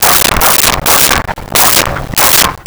Dog Barking 01
Dog Barking 01.wav